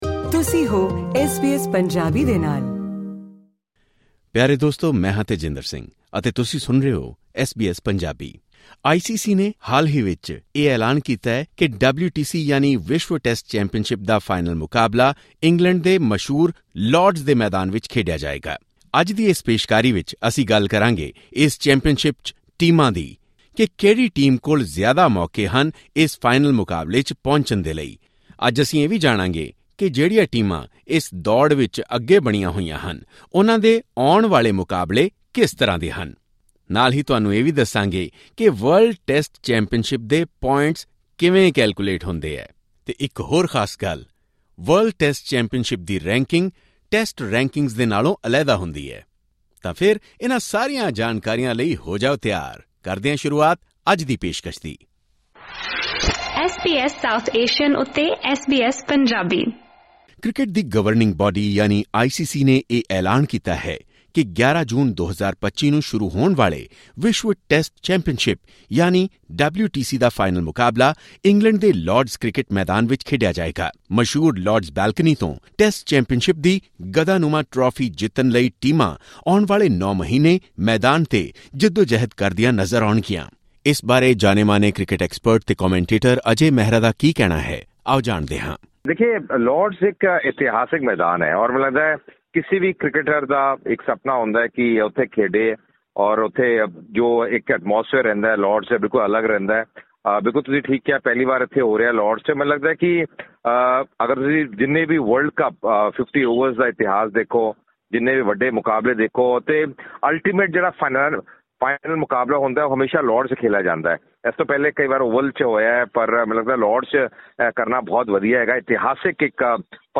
ਕ੍ਰਿਕੇਟ ਦੇ ਜਾਣਕਾਰ ਅਤੇ ਕੁਮੈਂਟੇਟਰ